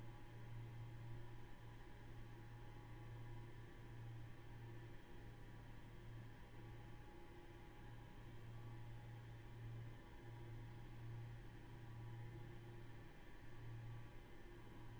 EC120_Fuel_Pump-left.wav